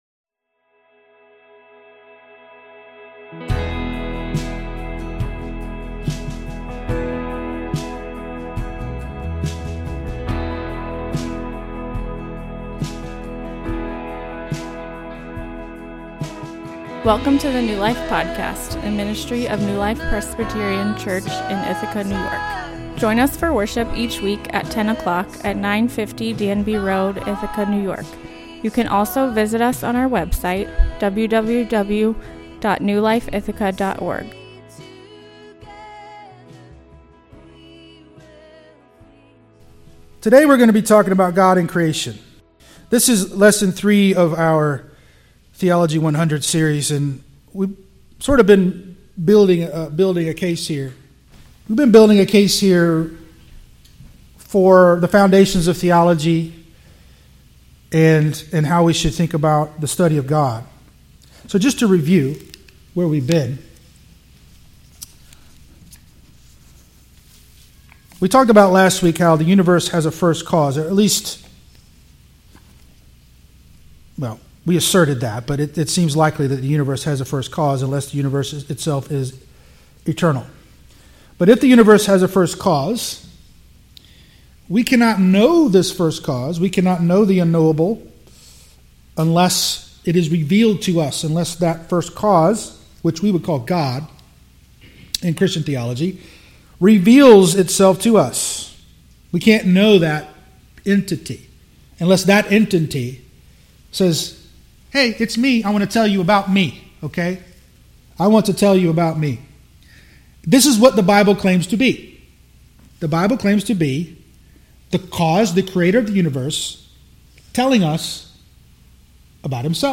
This is the fourth class in a five week Christian education class called Theo 100, an introduction to Christian Theology.